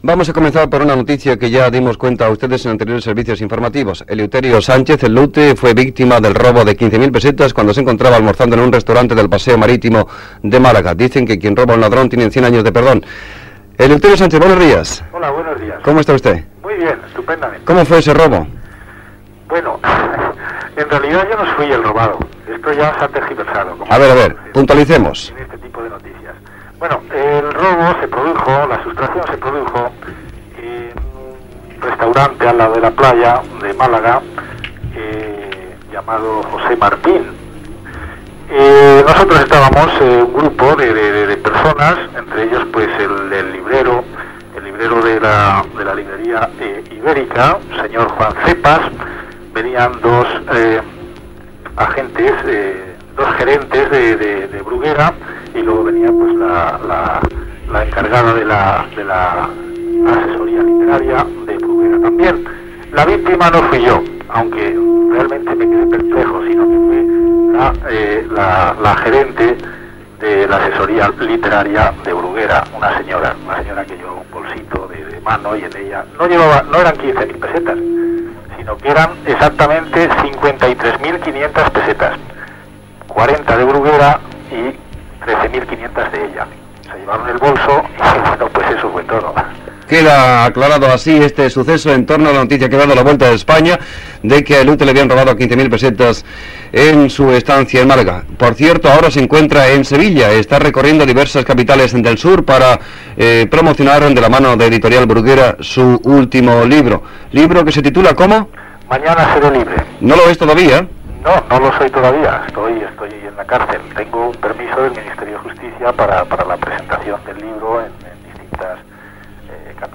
Entrevista a Eleuterio Sánchez "El Lute". Acabava de publicar el llibre "Mañana seré libre" i tenia un permís penitenciari per fer la seva presentació en diverses ciutats espanyoles